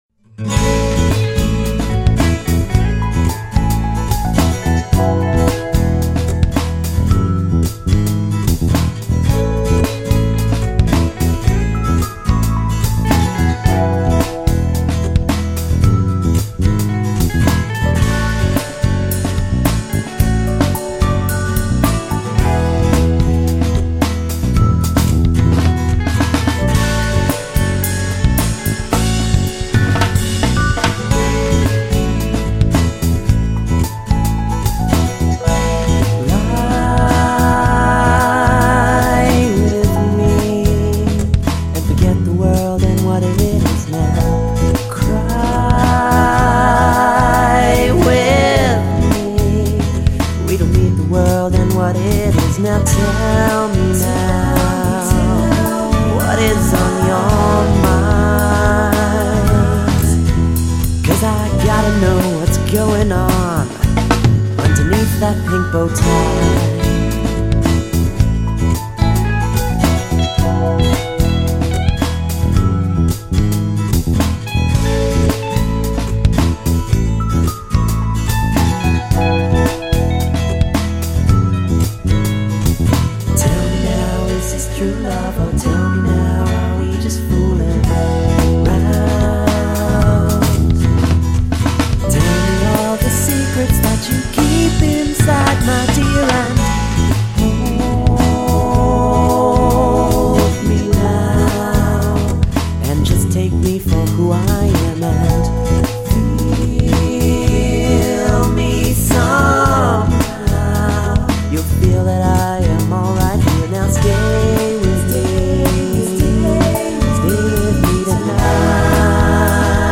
a nice, easy listening song